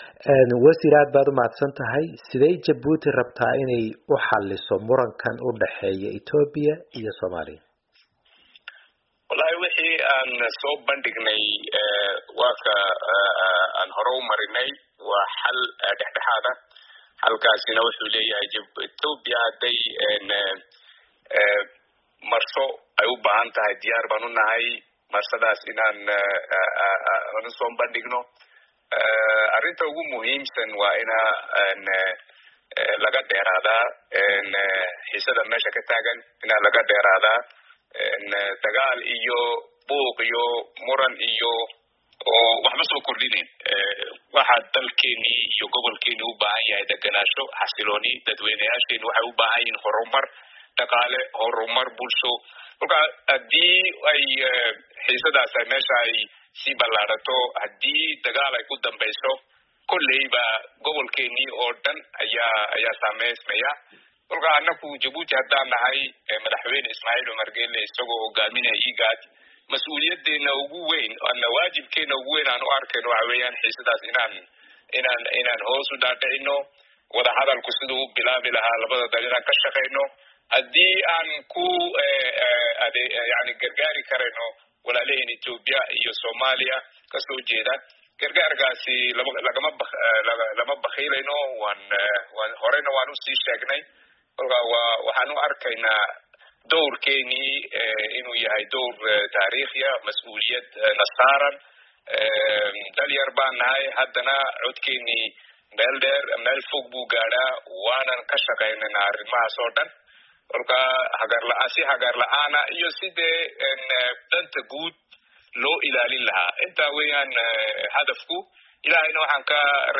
Wareysi: Wasiirka Arrimaha Dibedda Jabuuti Maxamuud Cali Yuusuf